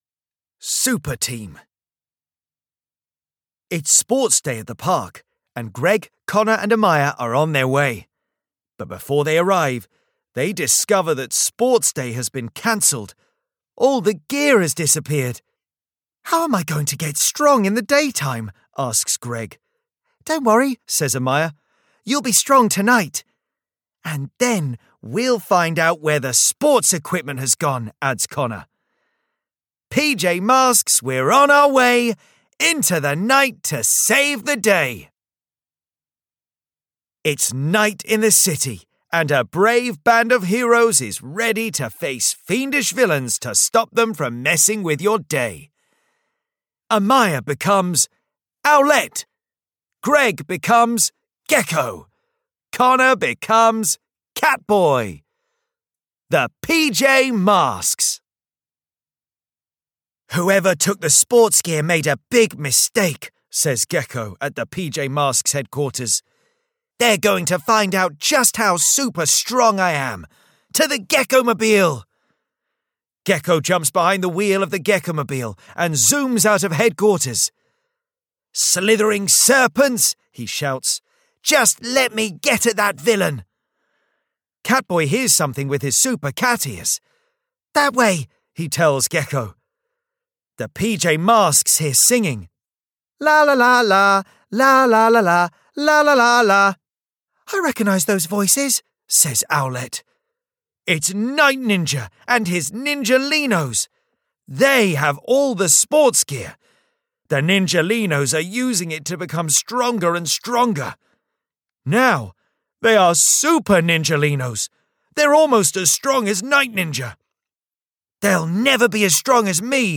PJ Masks - 5-Minute Stories (EN) audiokniha
Ukázka z knihy